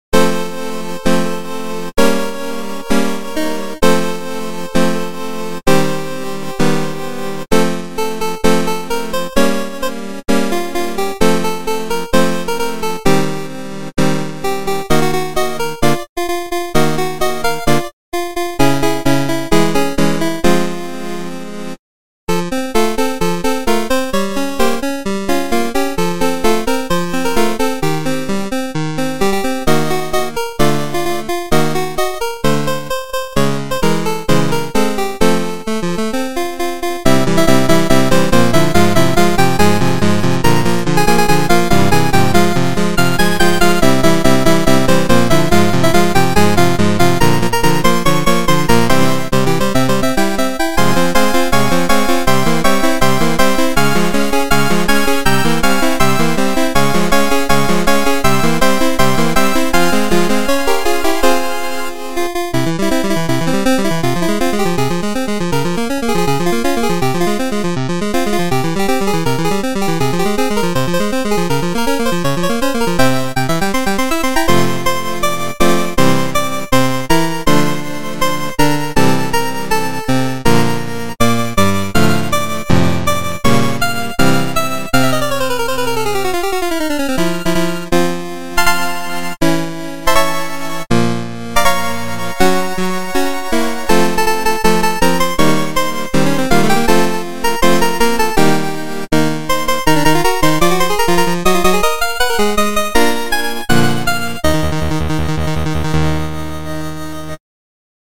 genre:chiptune
genre:remix